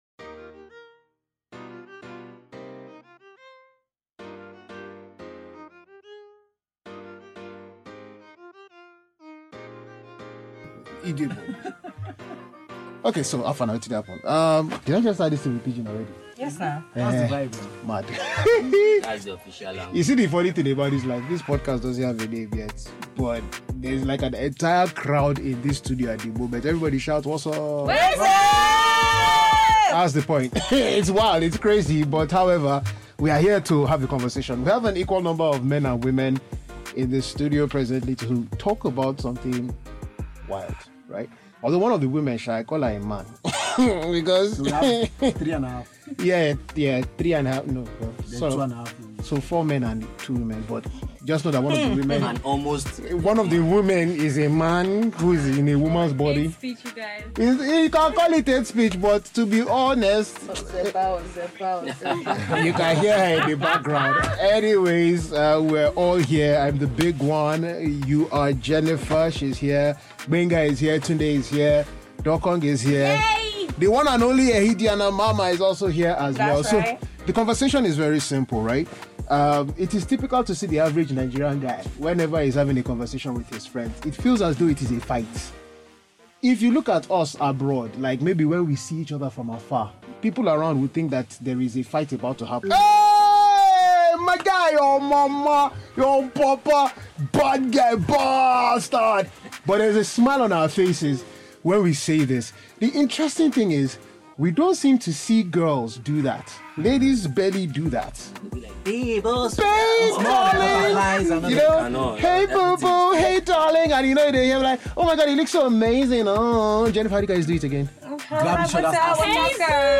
Now we can and will talk about everything, no holds barred, unedited (really really unedited, mistakes and all).